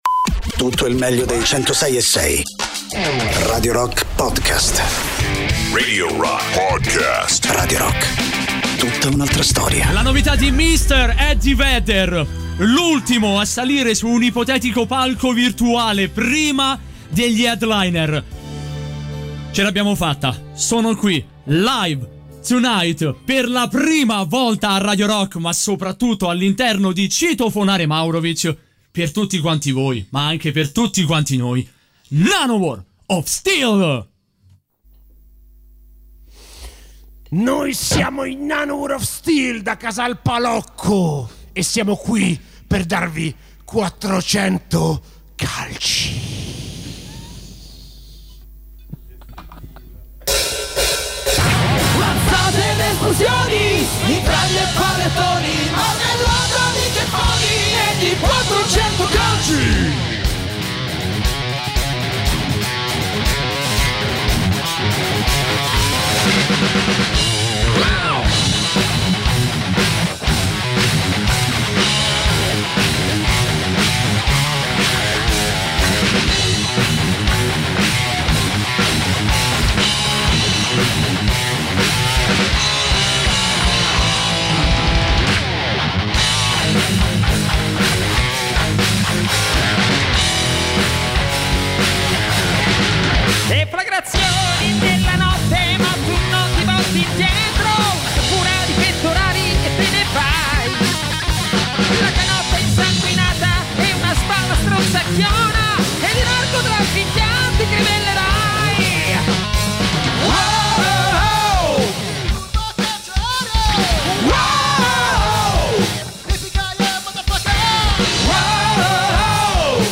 dal vivo
intervistati in studio